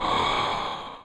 breath.ogg